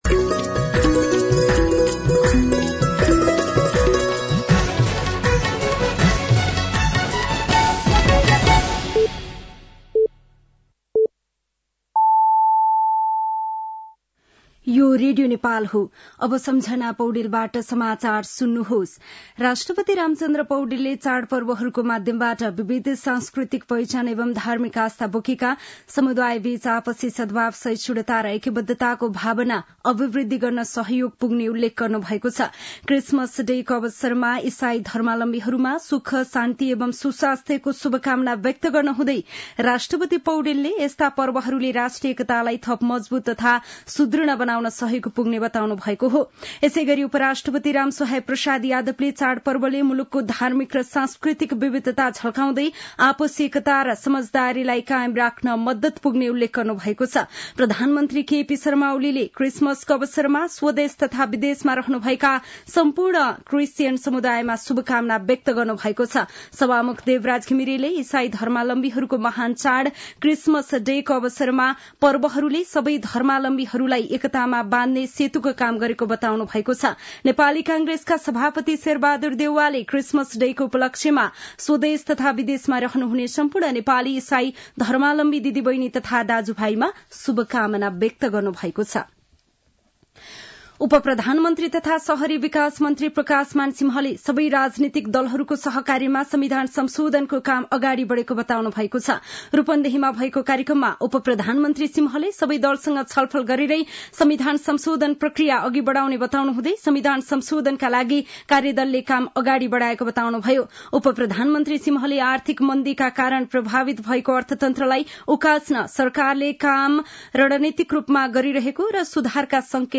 An online outlet of Nepal's national radio broadcaster
साँझ ५ बजेको नेपाली समाचार : ११ पुष , २०८१
5-PM-Nepali-News-9-10.mp3